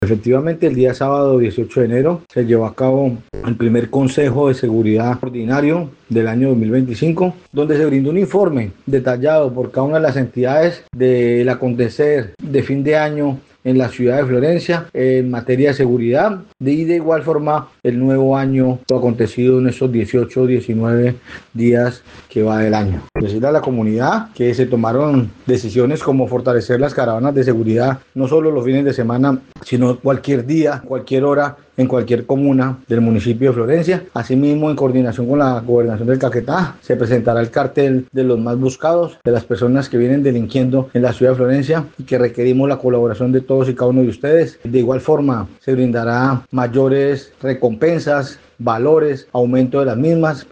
Así lo dio a conocer el secretario de gobierno municipal, Carlos Mora.